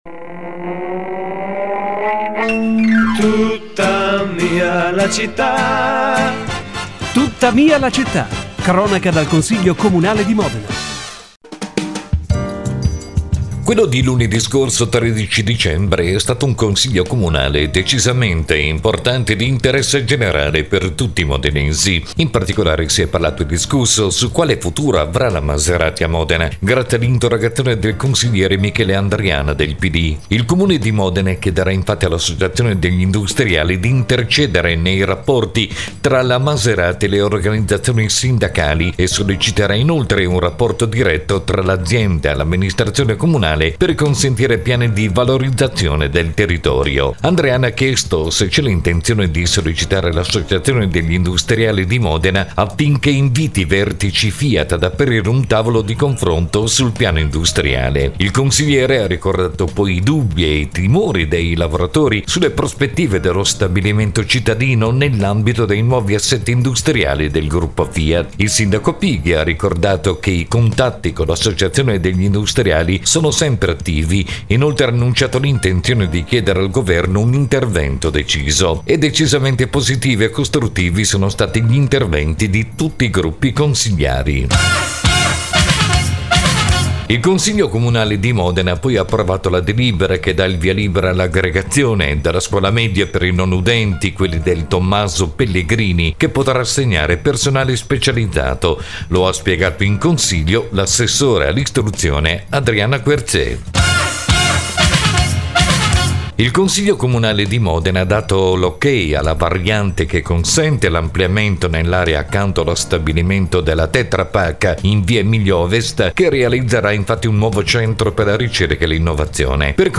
consiglio comunale 13 dicembre.mp3